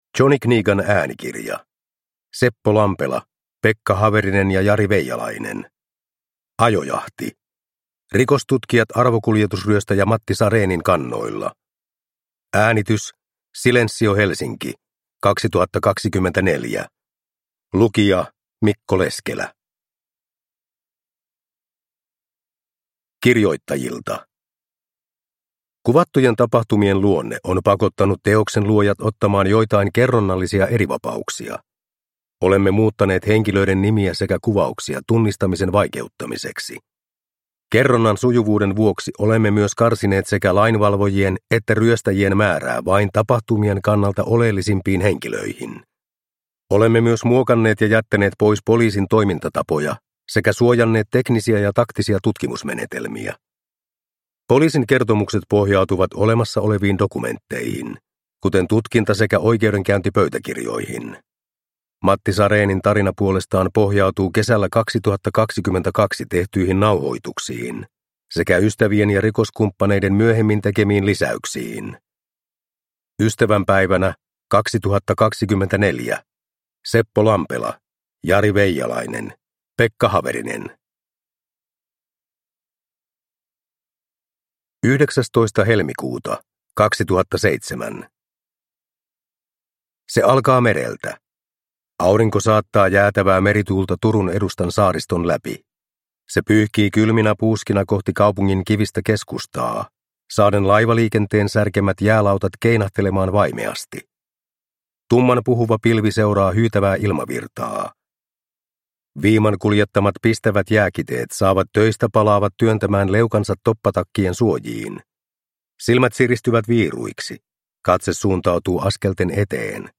Ajojahti (ljudbok) av Seppo Lampela